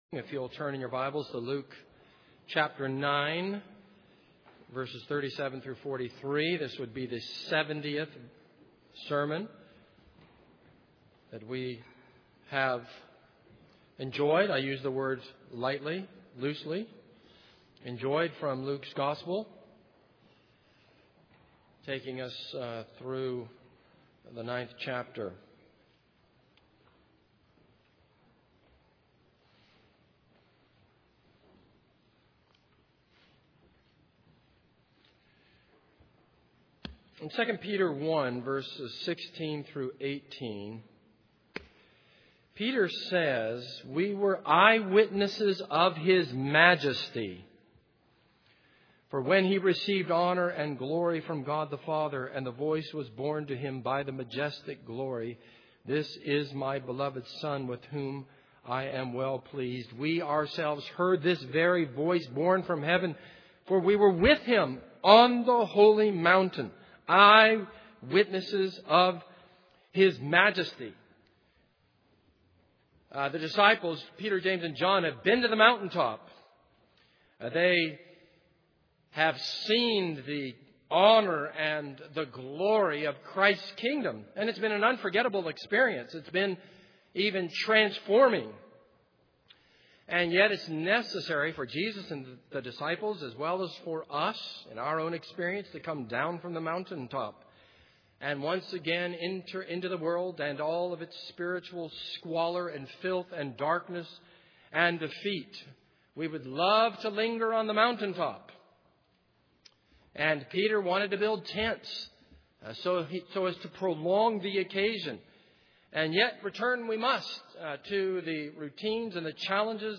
This is a sermon on Luke 9:37-43.